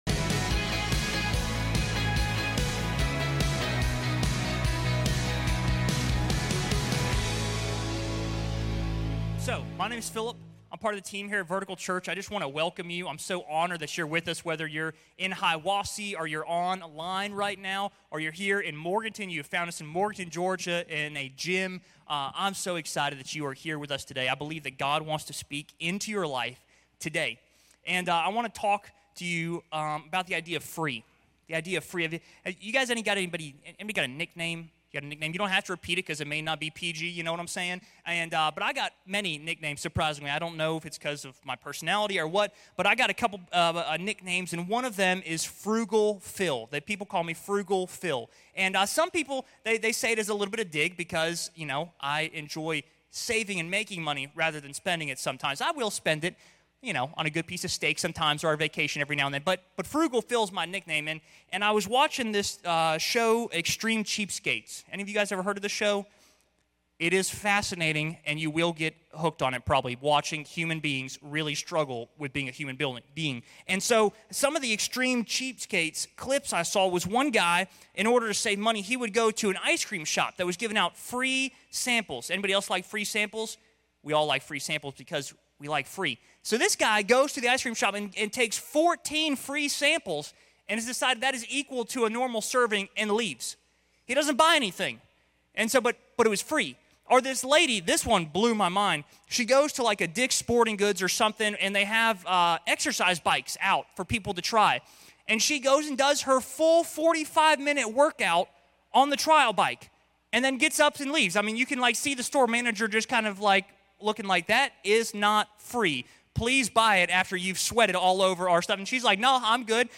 This sermon marks week 2 of "What Would You Say?"